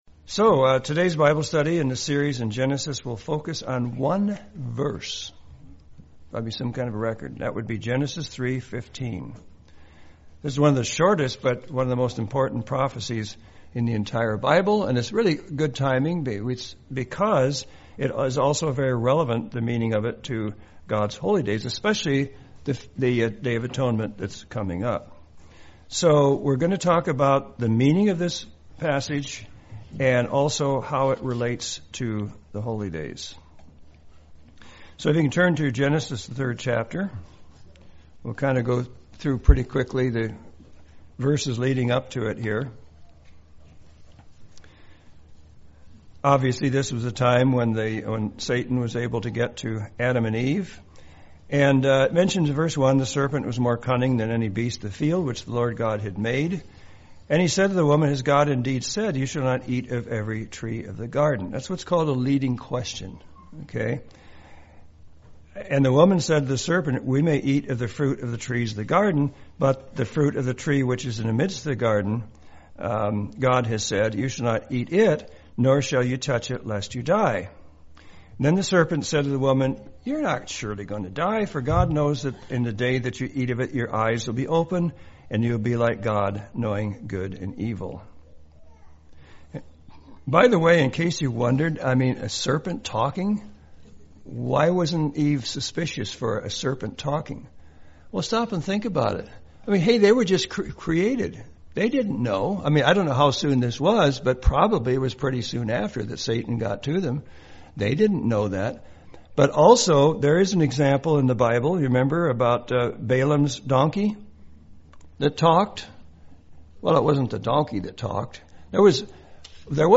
Given in Medford, OR